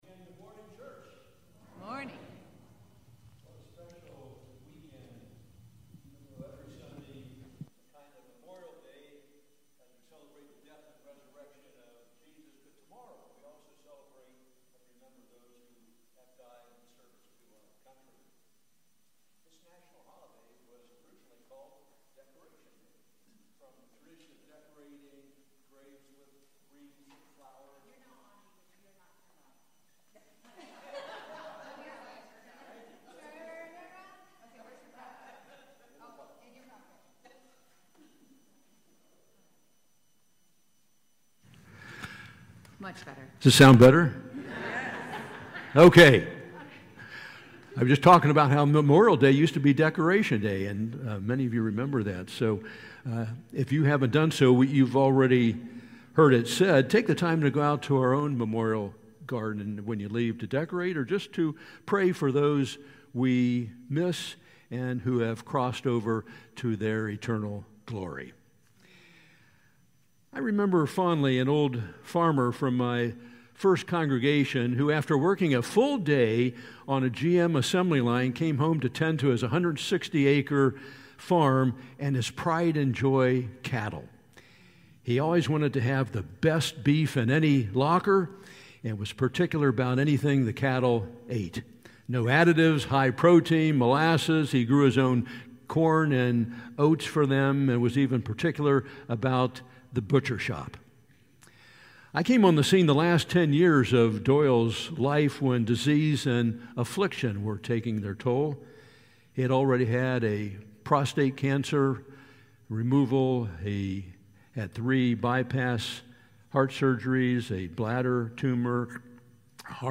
May 26, 2024 Sermon